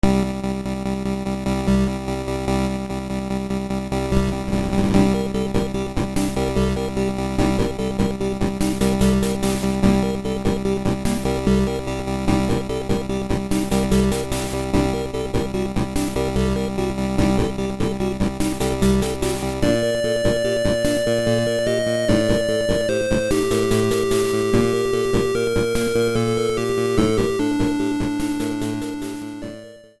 Underwater theme
Fair use music sample